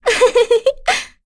Yuria-Vox_Happy2.wav